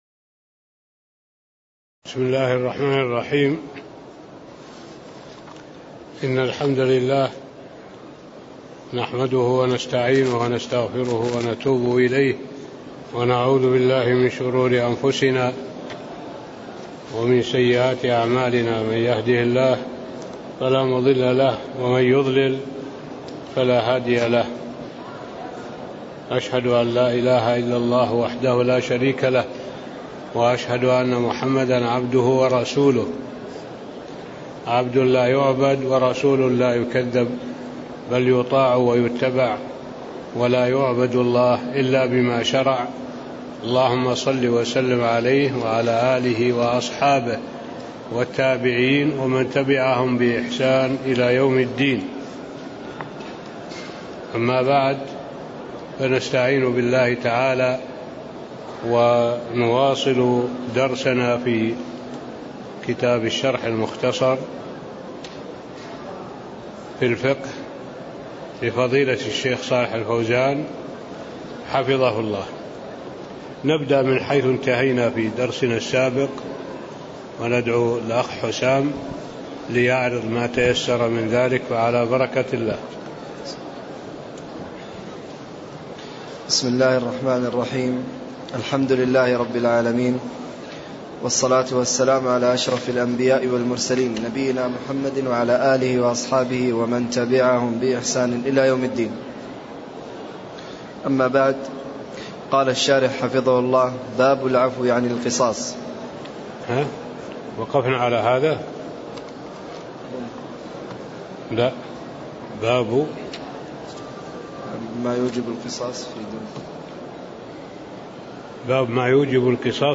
تاريخ النشر ١٤ شوال ١٤٣٥ هـ المكان: المسجد النبوي الشيخ: معالي الشيخ الدكتور صالح بن عبد الله العبود معالي الشيخ الدكتور صالح بن عبد الله العبود باب ما يوجب القصاص فيما دون النفس (03)